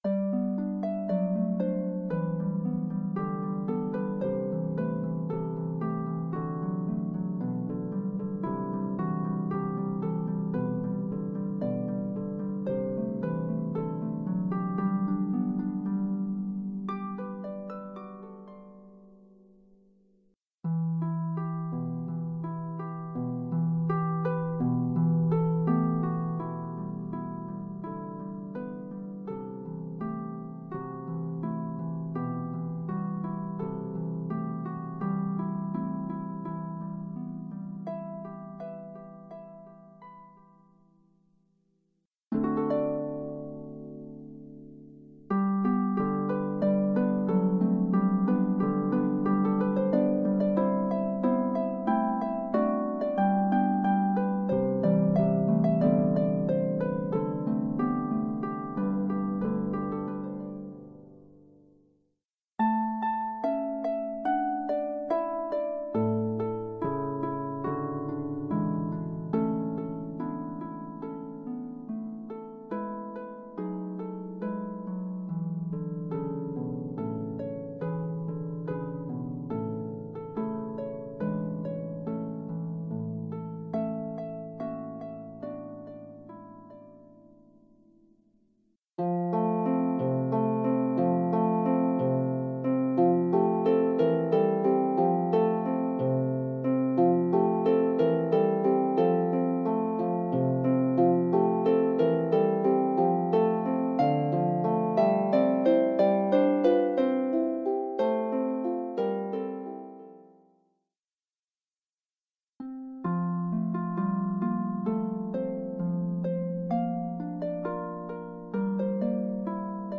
Welsh folksongs
solo lever or pedal harp